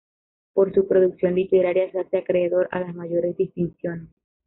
pro‧duc‧ción
/pɾoduɡˈθjon/